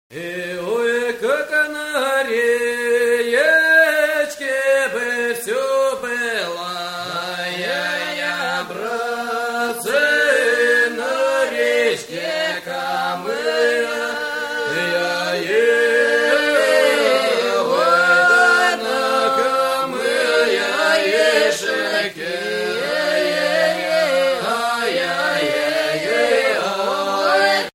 - Traditional songs of Cossacks
historical song